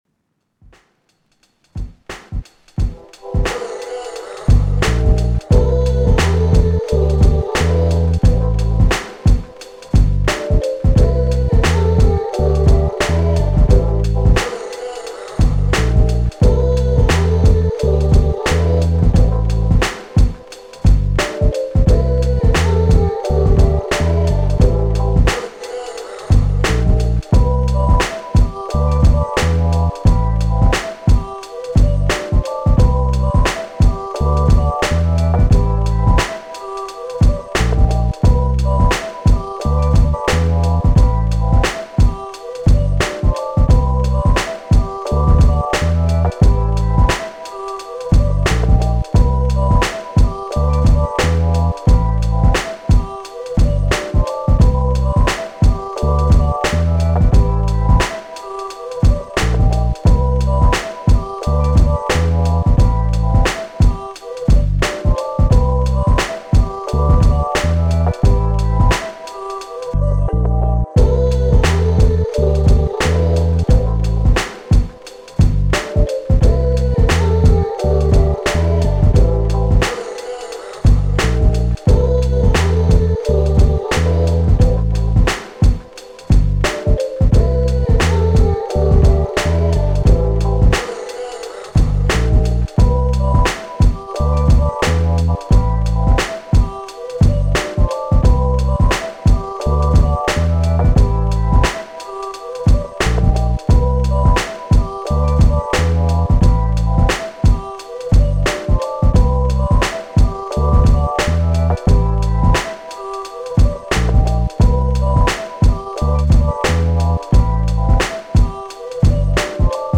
Hip Hop, R&B, 90s
D Minor Enemies watching